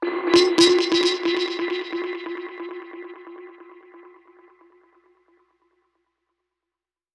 Index of /musicradar/dub-percussion-samples/134bpm
DPFX_PercHit_B_134-04.wav